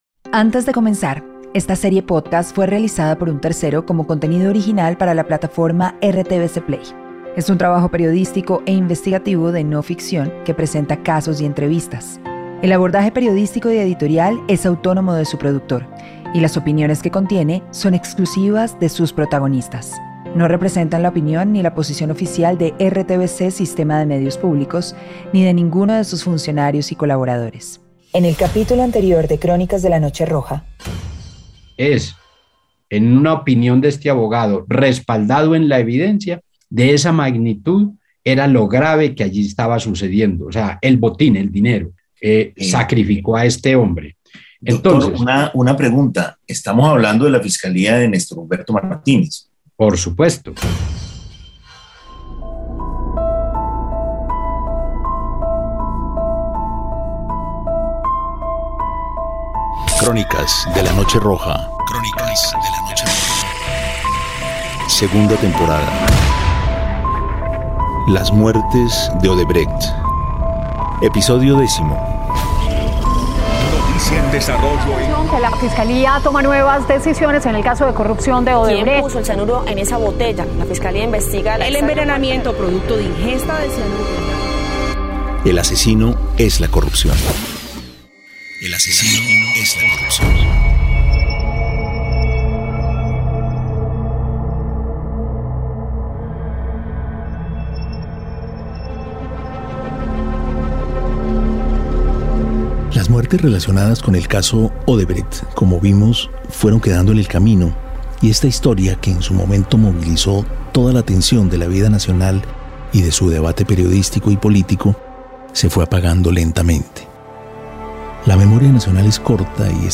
Entrevista a Mauricio García Villegas.